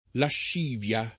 lascivia [ lašš & v L a ] s. f.